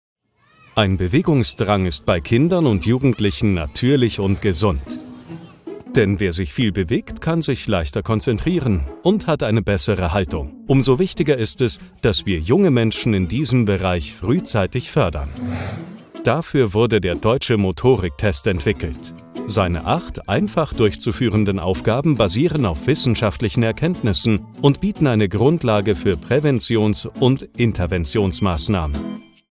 Kommerziell, Natürlich, Zuverlässig, Freundlich, Corporate
Erklärvideo